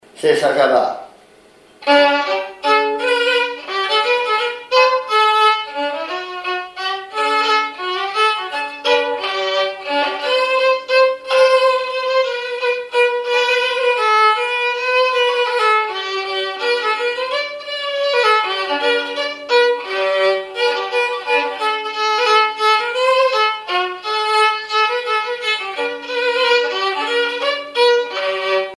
Mémoires et Patrimoines vivants - RaddO est une base de données d'archives iconographiques et sonores.
violoneux, violon
Flocellière (La)
danse : java